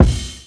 CDK - Crash Kick 2.wav